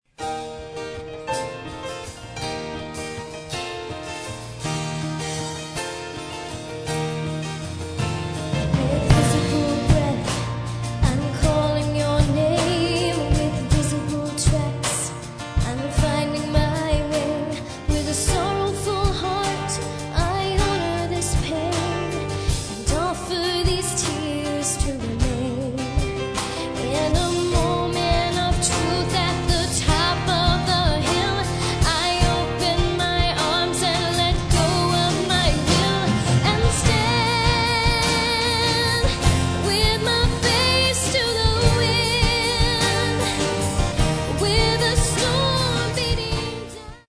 6 Köpfige Band aus den USA
Moderner Musikstil.
E-& A-Gitarre, Bass, Piano, Keyboard, Drums, 5 Stimmen